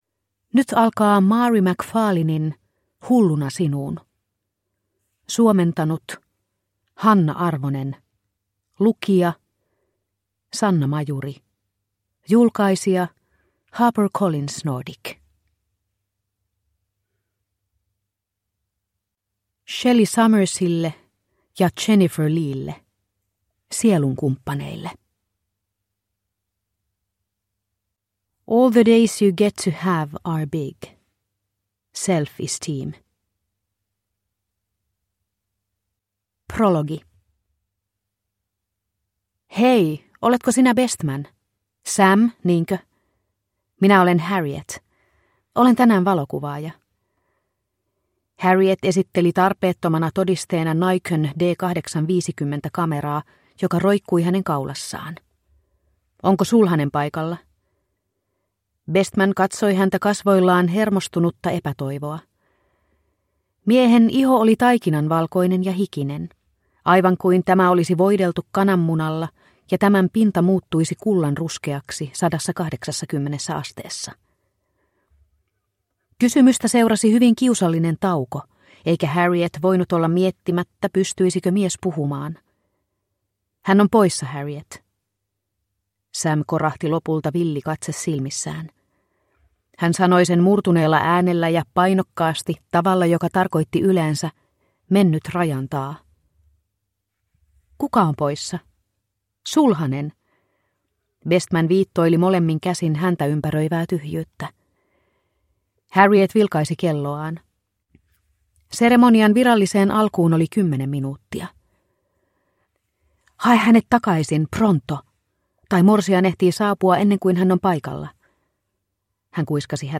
Hulluna sinuun – Ljudbok – Laddas ner